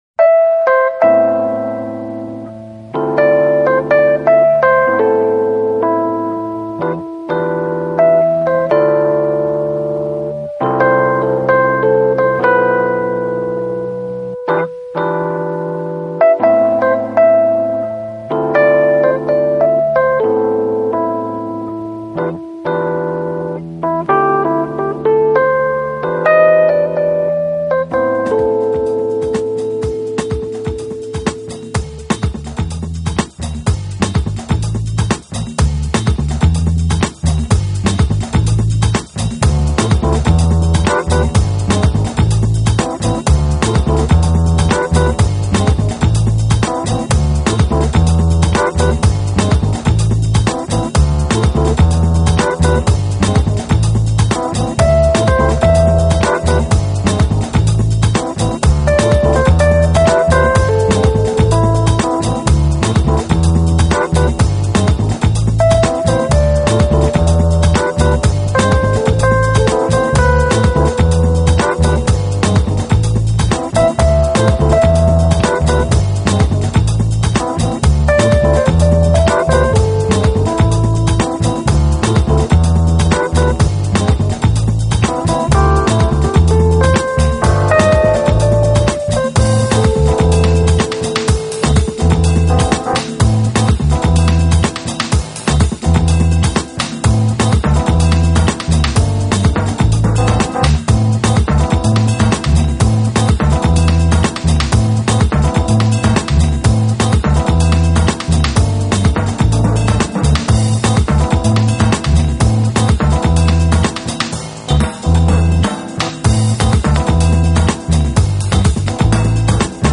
并不熟悉，但是绝对原汁原味呈现出南欧民族色彩，并交织缓拍／迷幻／浩室美态于一身
CD1 Ethno Moods